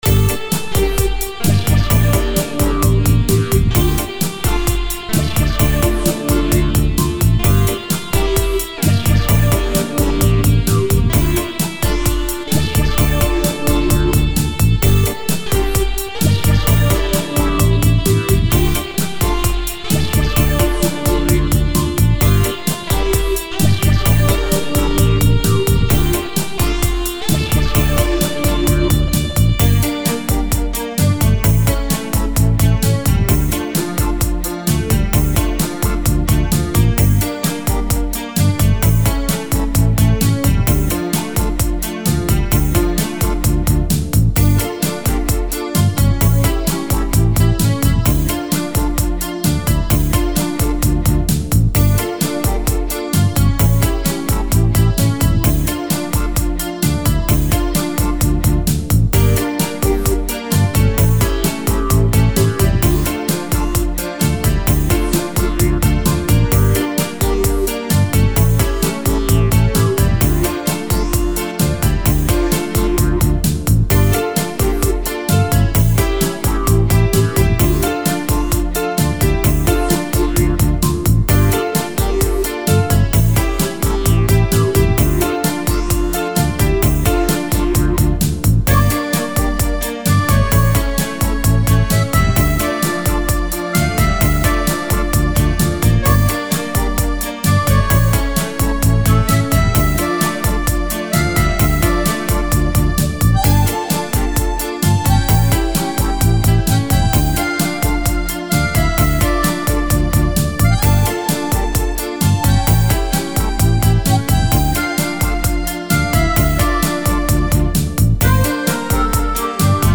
Электроника: посоветуйте сведение и т.д.
Новичок, сваял "шедевр" - какая-то электроника.